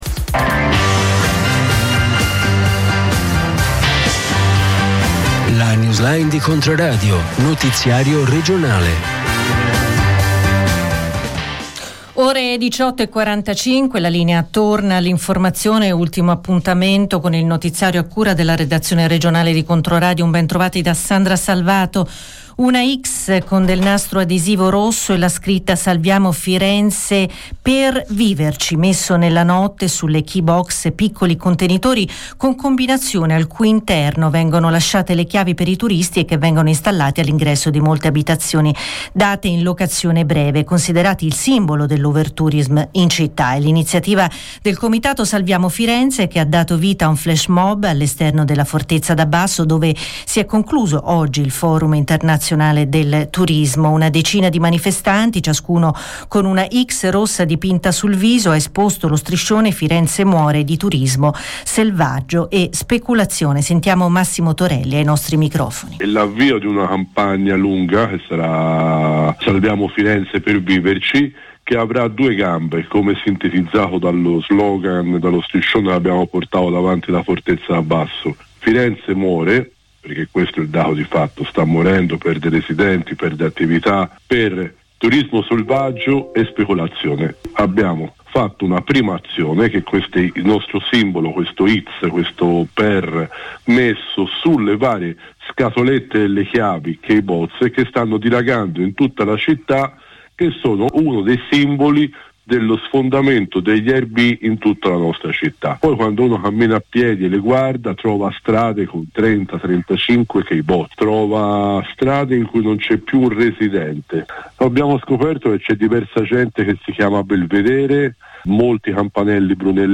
Notiziario regionale